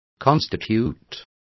Also find out how constituirás is pronounced correctly.